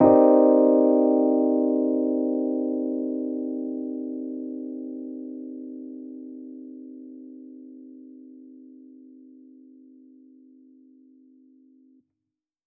Index of /musicradar/jazz-keys-samples/Chord Hits/Electric Piano 2
JK_ElPiano2_Chord-Cm9.wav